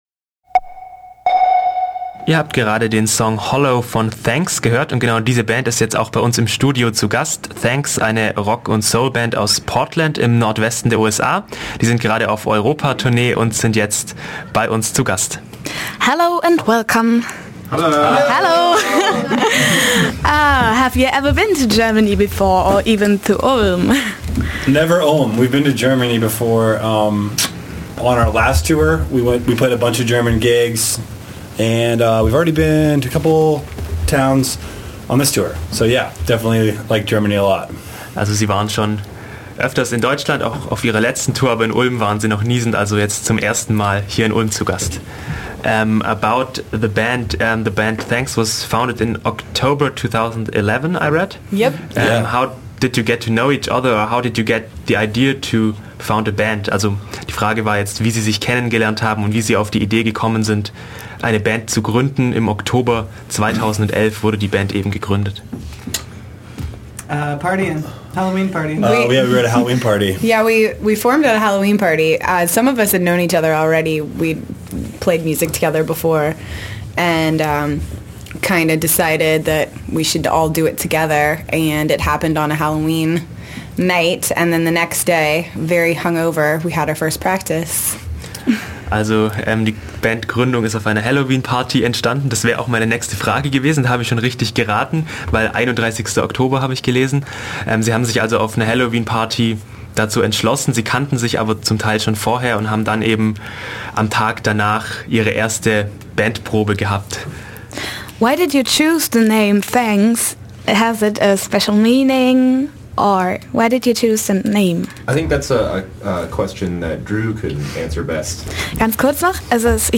Interview mit Thanks
Am Dienstag, den 18. November, war bei uns in der Ulmer Freiheit die Band "Thanks" zu Gast.
interview_thanks.mp3